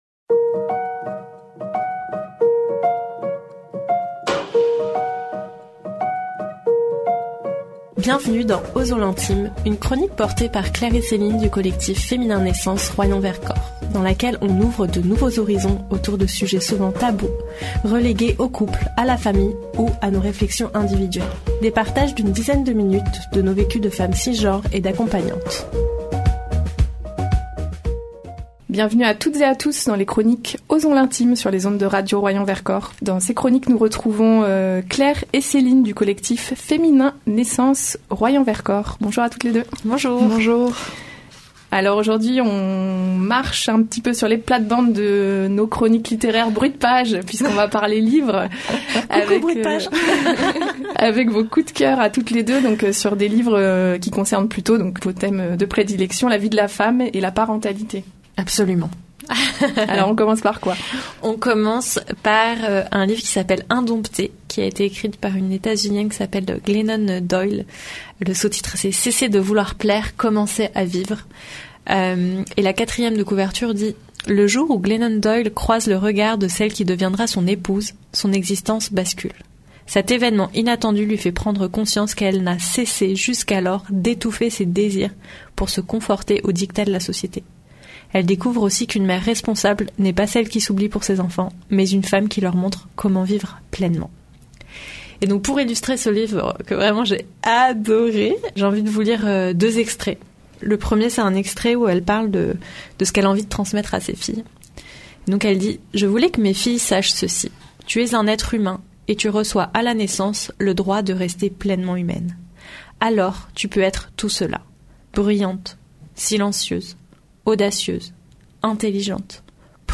Des partages d’une dizaine de minutes autour de leurs vécus de femmes cisgenres et d’accompagnantes.